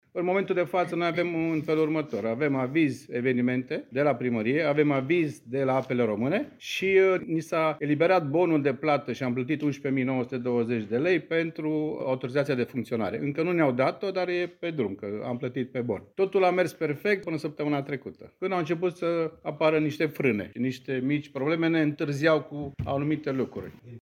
Într-o conferință de presă organizată cu ocazia deschiderii oficiale a sezonului estival de pe litoral